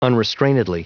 Prononciation du mot unrestrainedly en anglais (fichier audio)